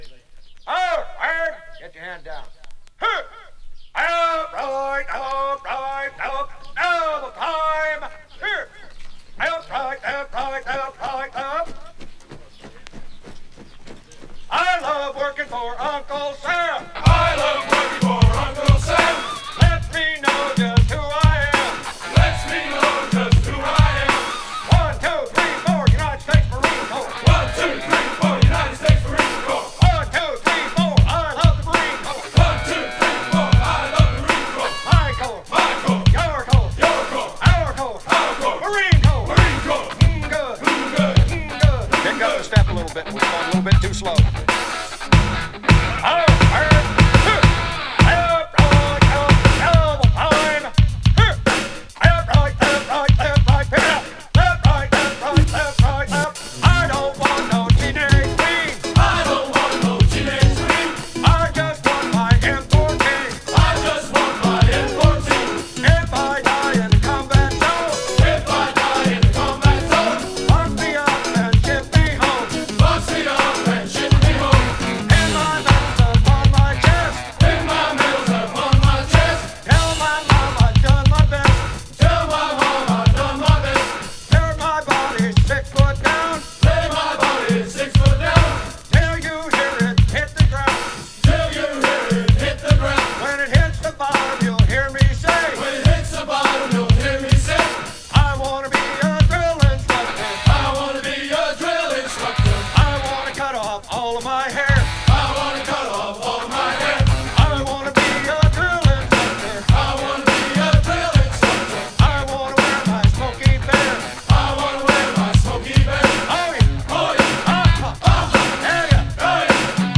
コール＆レスポンスで盛り上がるUSマリーン・ブレイクス！